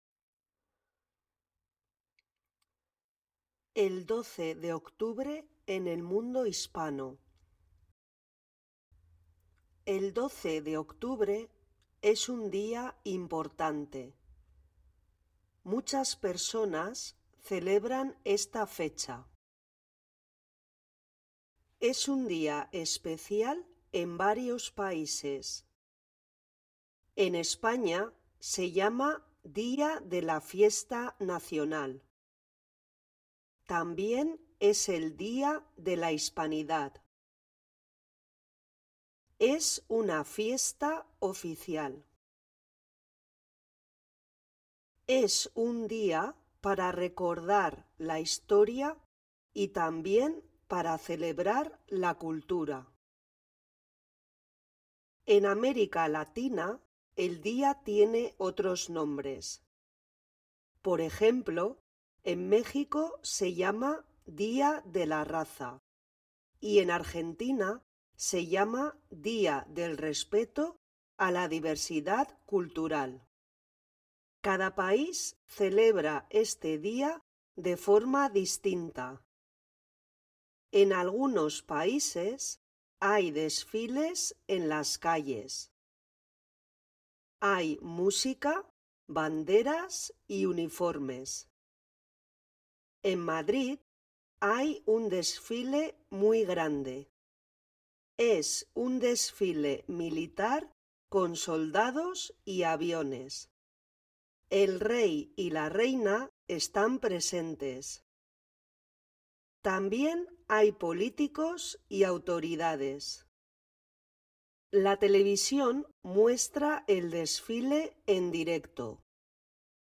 Spanish online reading and listening practice – level A1